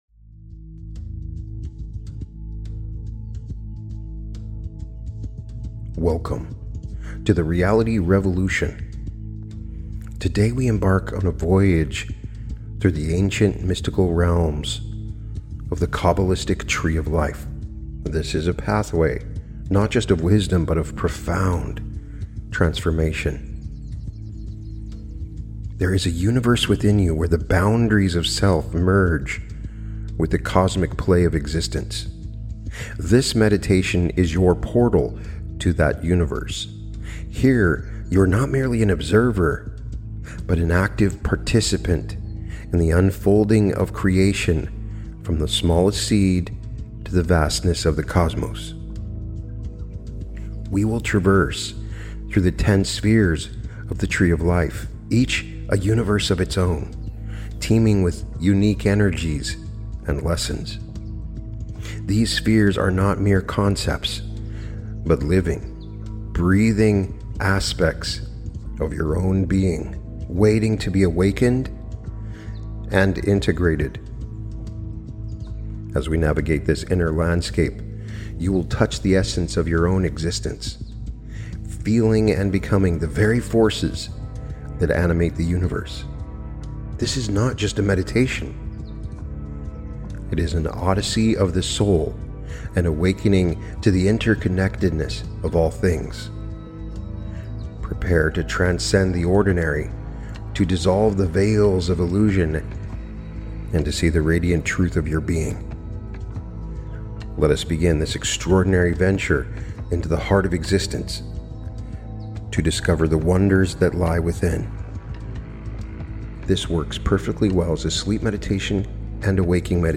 Guided Meditation - Journey Through The Tree Of Life